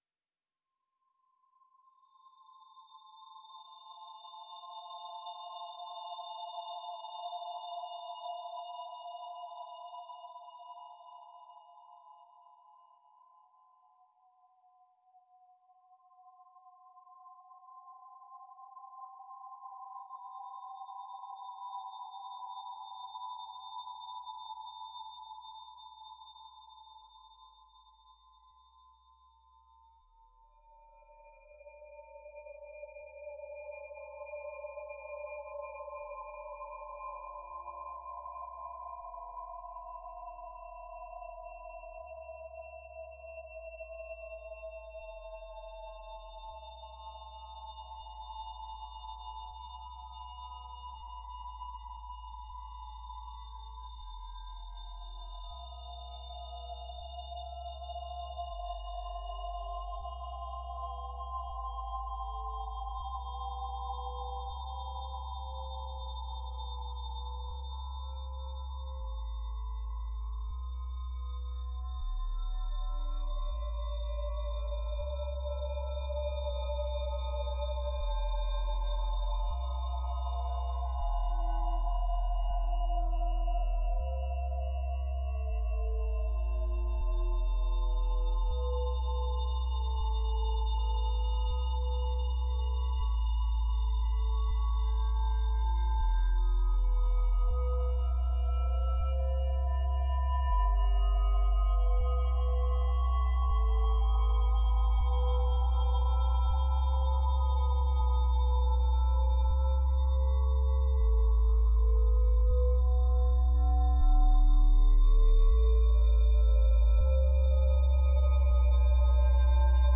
Composer and sound artists